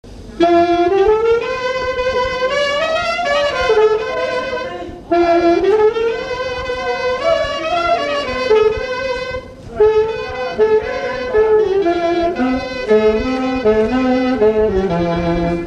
Chaillé-sous-les-Ormeaux
instrumental
danse
Pièce musicale inédite